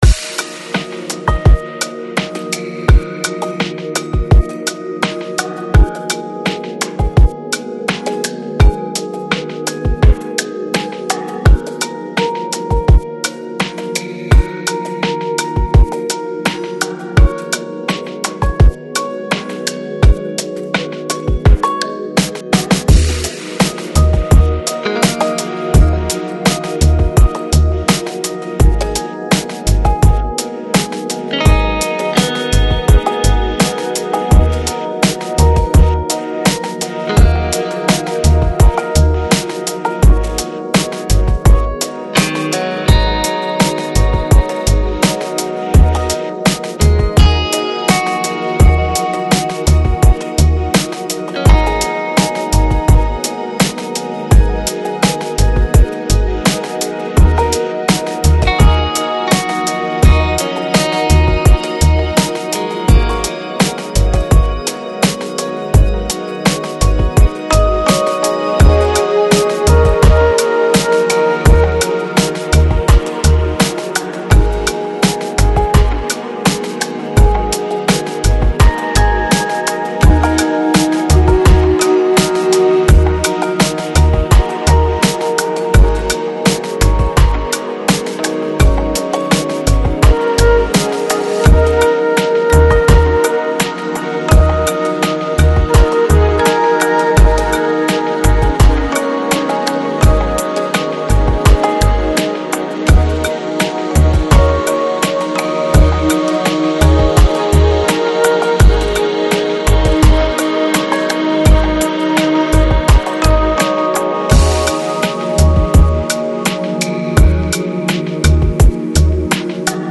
Electronic, Lounge, Downtempo, Chill Out